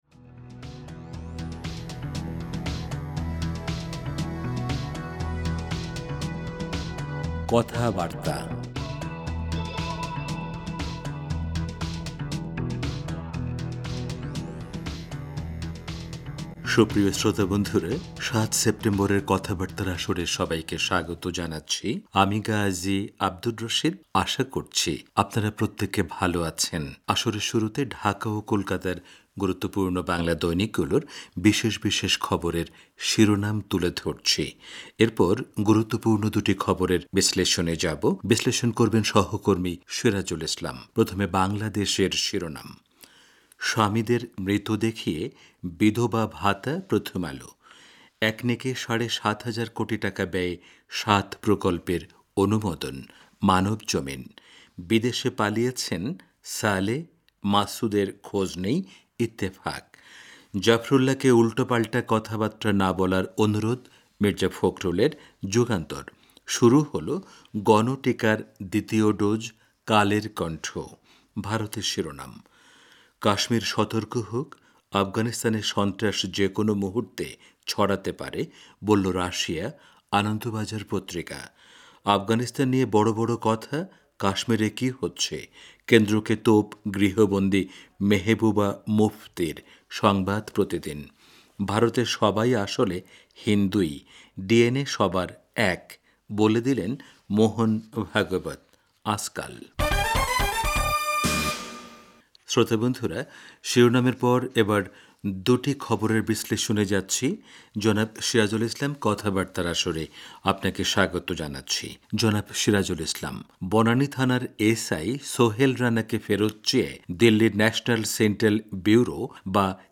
রেডিও অনুষ্ঠানমালা